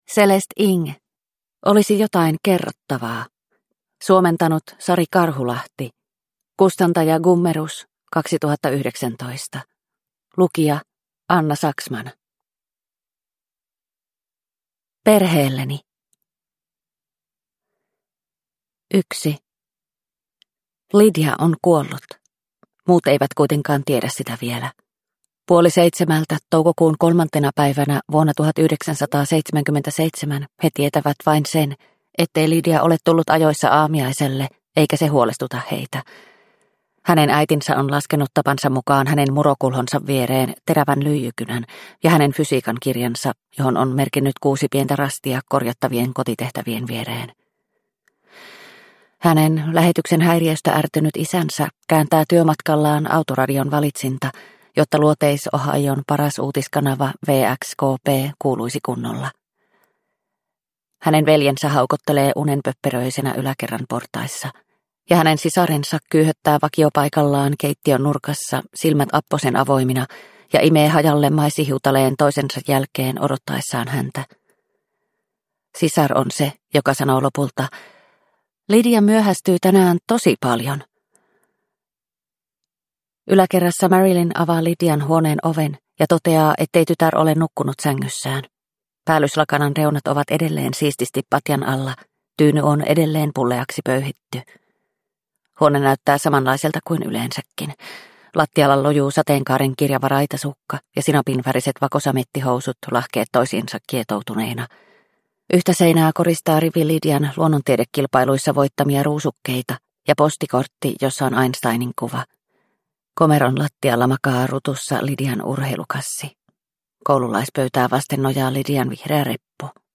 Olisi jotain kerrottavaa – Ljudbok – Laddas ner